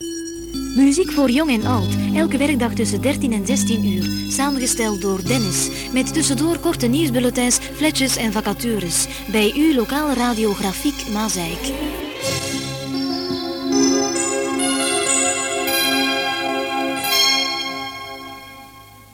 Here are some jingles.